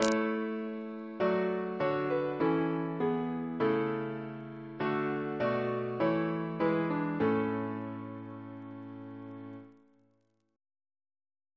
CCP: Chant sampler
Single chant in A Composer: James Nares (1715-1783) Reference psalters: ACB: 166; ACP: 29 277; CWP: 83; H1982: S20 S44; OCB: 63; PP/SNCB: 122; RSCM: 198